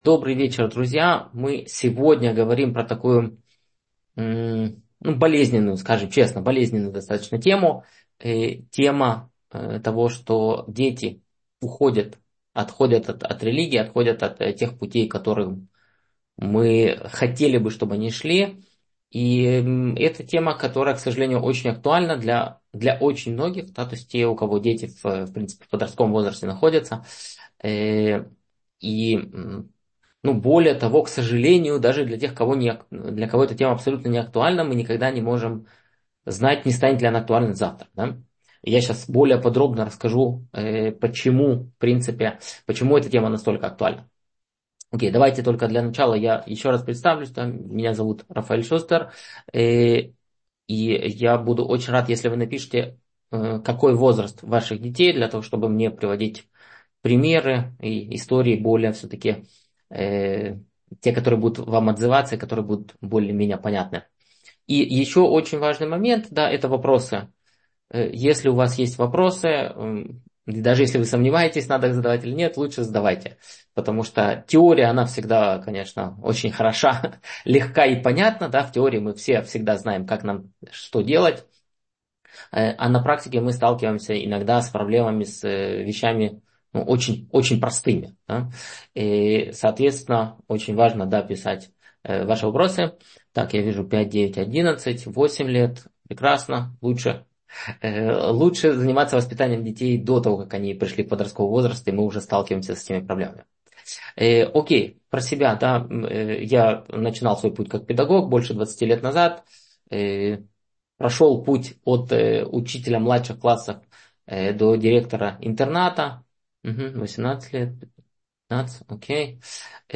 Почему дети уходят от религии — слушать лекции раввинов онлайн | Еврейские аудиоуроки по теме «Еврейская семья» на Толдот.ру Whatsapp и Telegram !